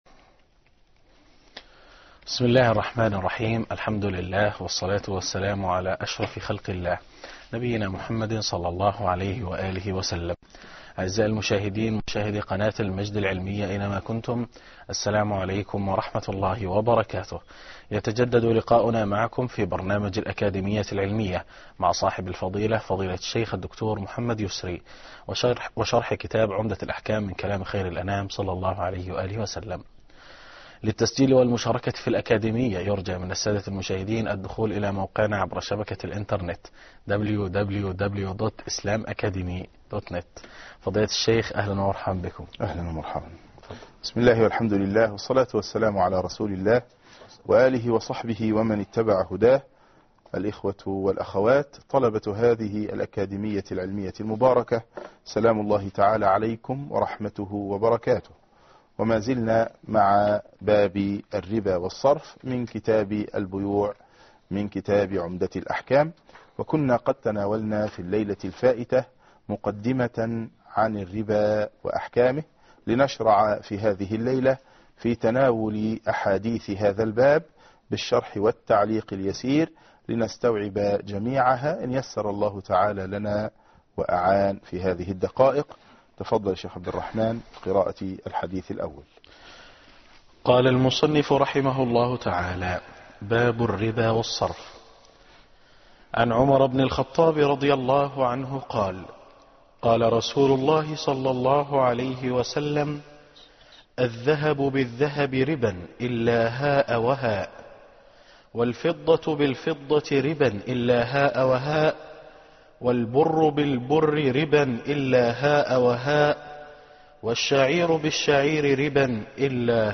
الدرس 20 _ الأحاديث في باب الربا والصرف